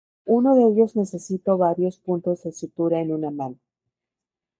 /suˈtuɾa/